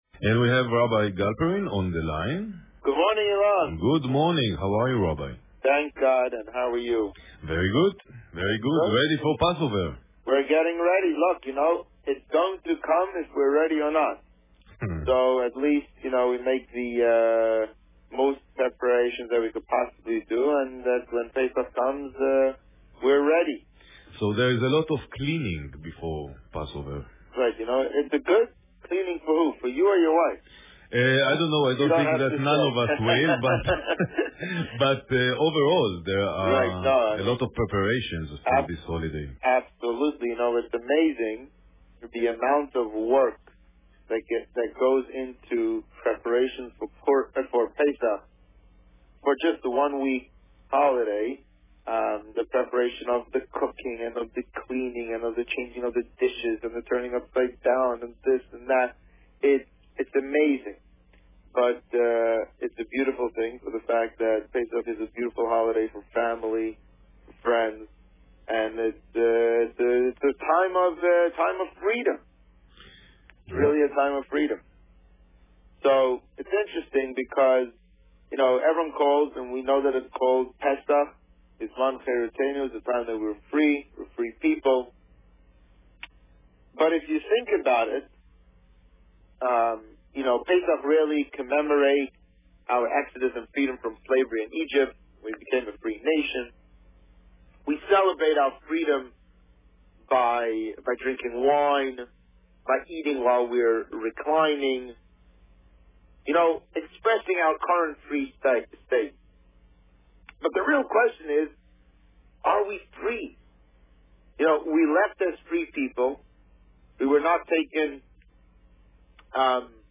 Preparation for Pesach 2014 - Part 2 Published: 17 April 2014 | Written by Administrator This week's interview was obviously pre-recorded, as it deals with more preparations for the Pesach seders (despite the seders having already taken place).